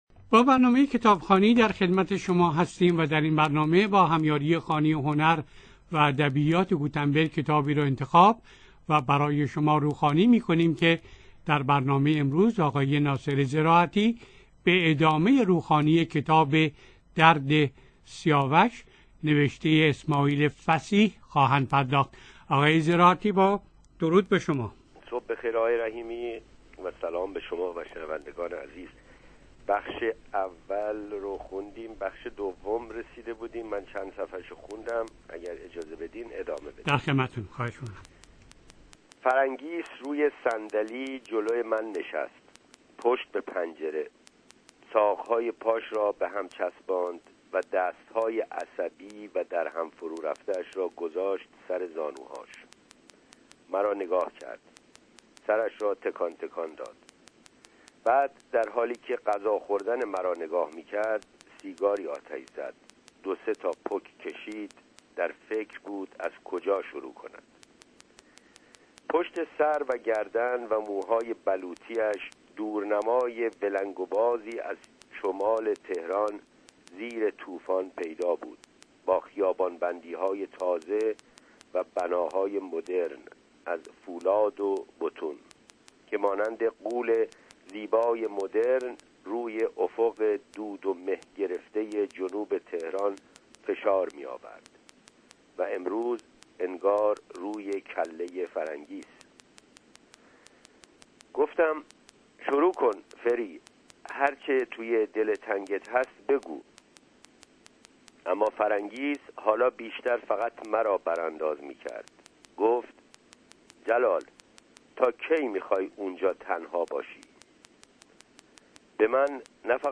در برنامه کتابخوانی رادیو سپهر
در 21 بخش روخوانی